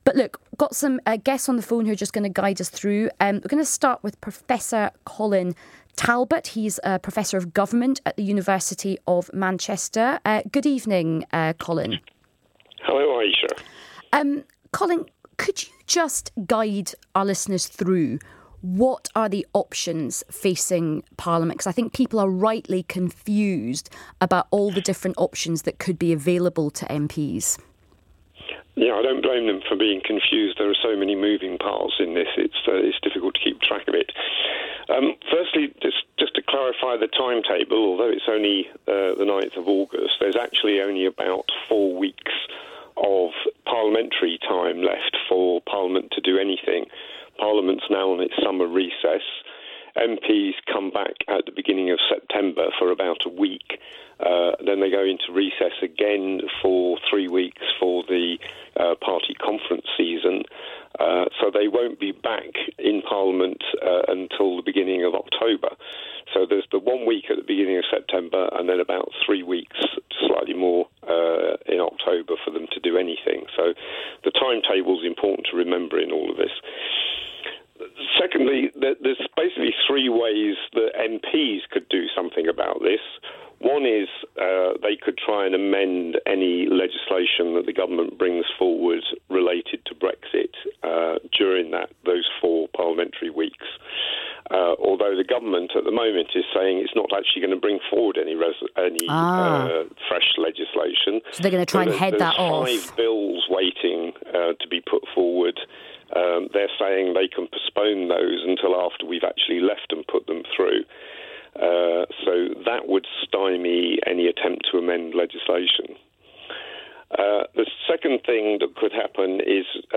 My discussion with Ayesha Hazarika on LBC radio on 9 August 2018.